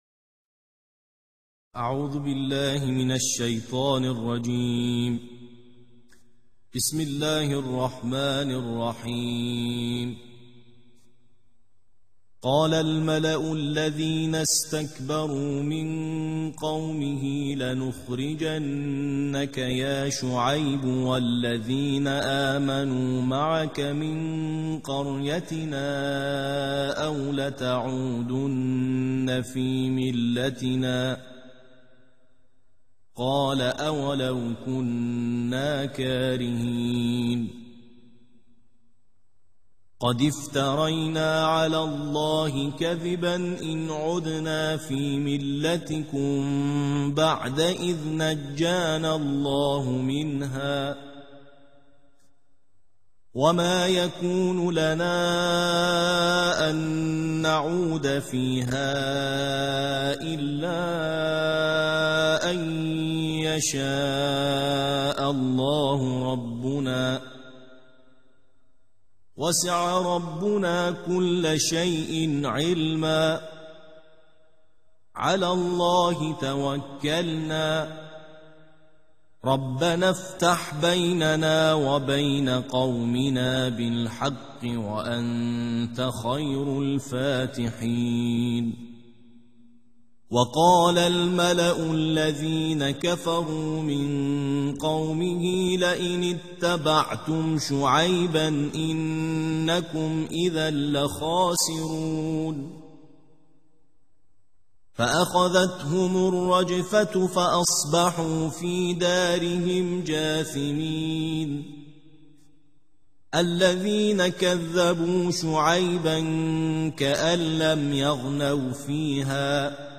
ترتیل جزء نهم